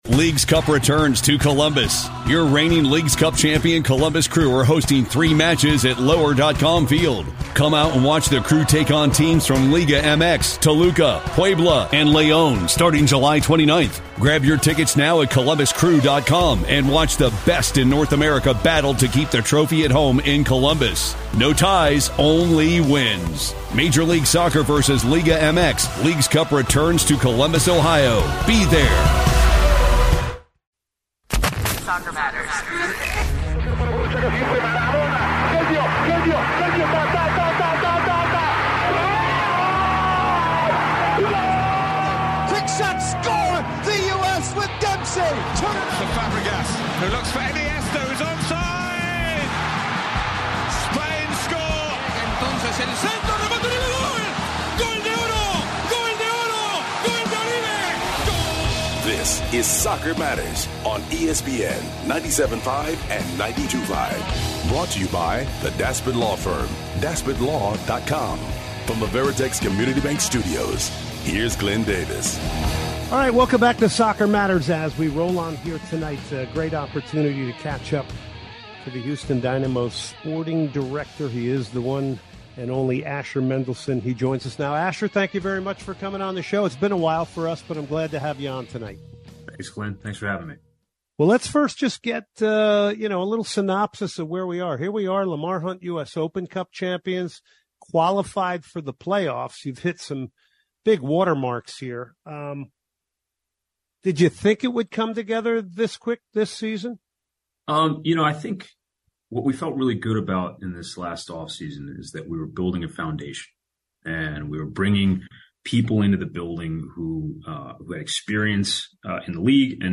two interviews: